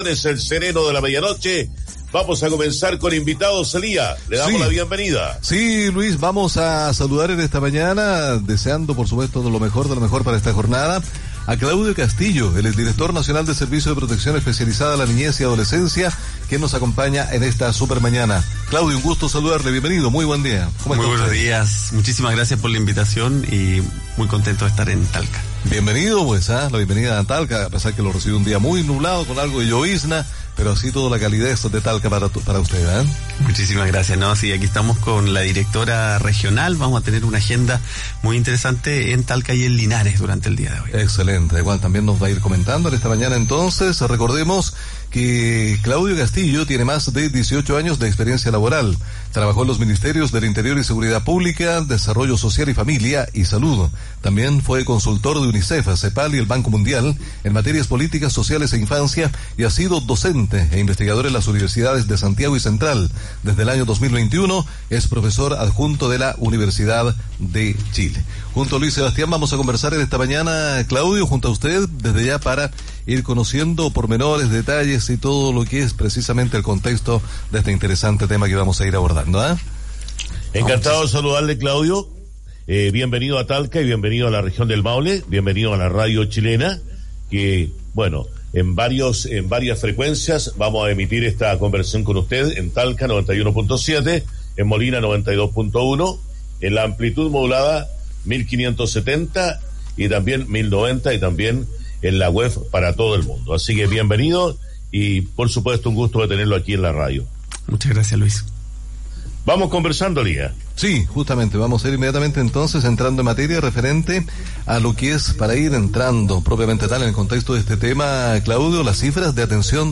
Durante su visita a la región del Maule, el director del Servicio de Protección Especializada a la Niñez y Adolescencia, Claudio Castillo, realizó una entrevista en Radio Chilena del Maule, donde habló sobre los cambios legislativos a los procesos de adopción, sobre potenciar el programa Familias de Acogida y otros desafíos que tiene la institución.